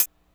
hihat02.wav